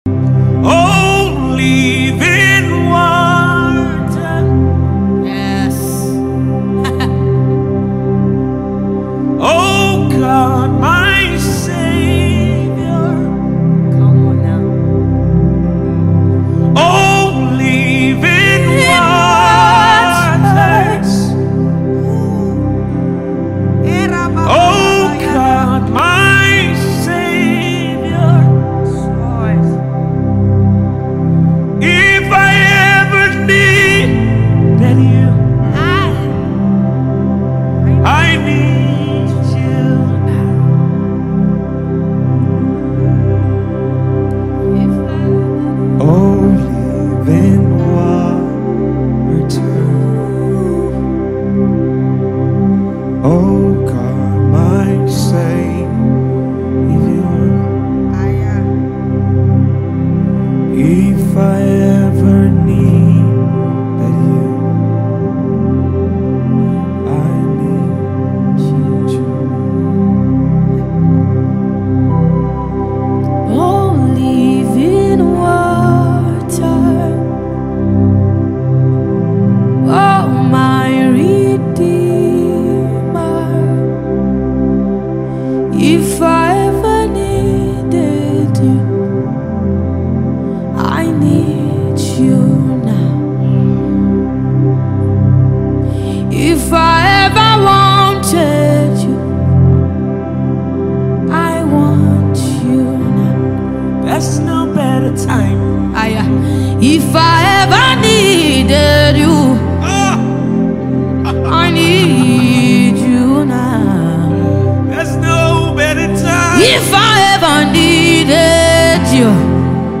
inspiration song
that will lift your spirit